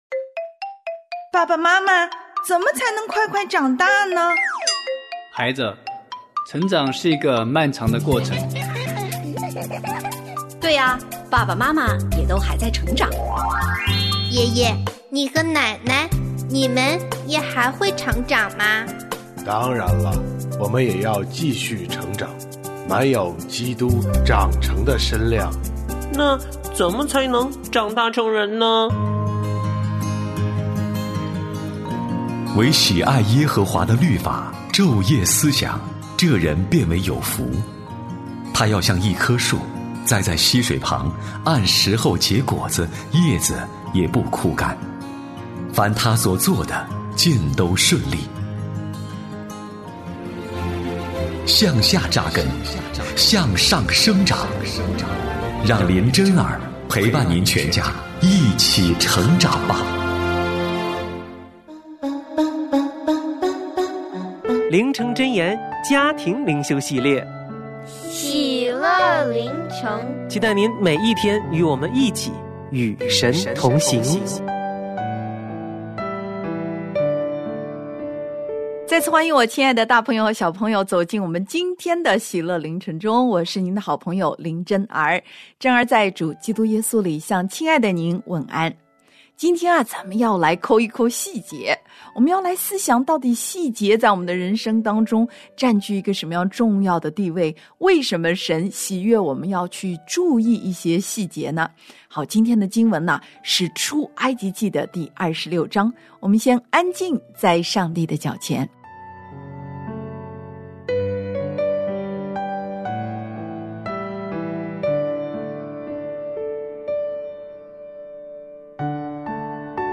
我家剧场：圣经广播剧（157）亚玛谢向约阿施挑战；希西家做犹大王